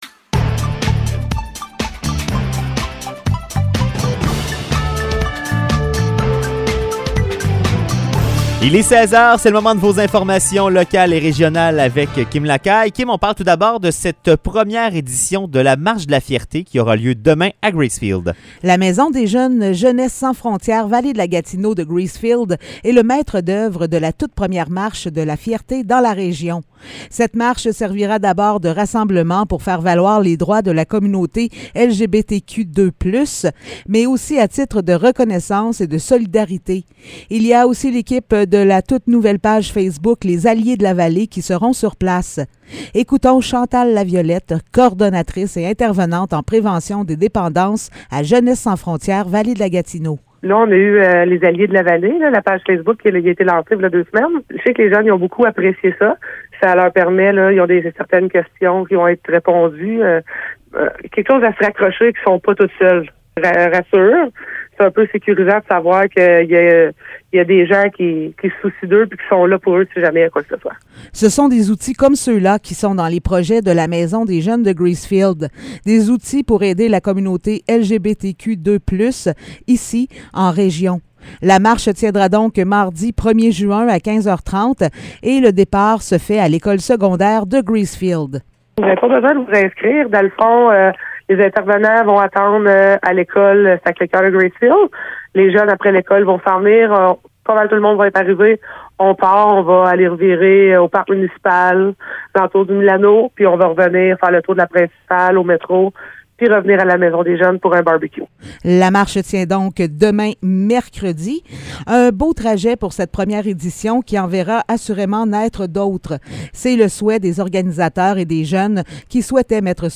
Nouvelles locales - 31 mai 2022 - 16 h